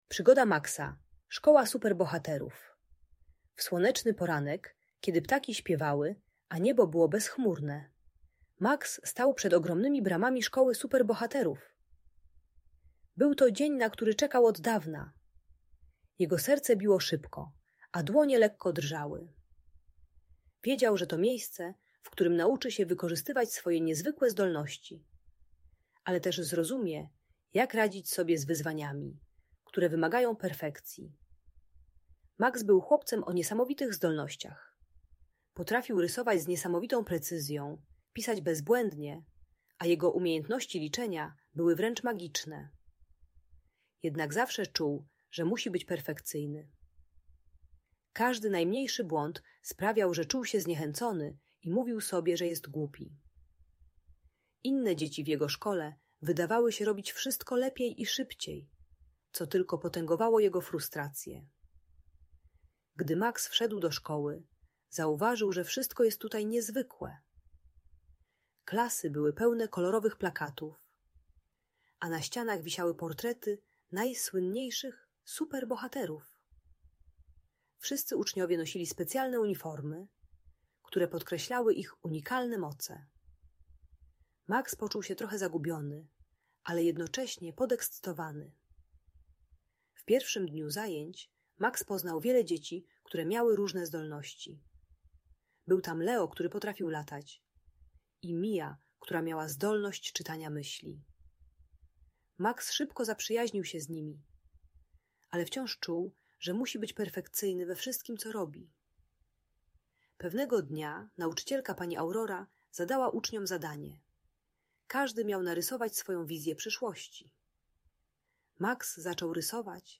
Przygoda Maxa: Szkoła Super Bohaterów - Audiobajka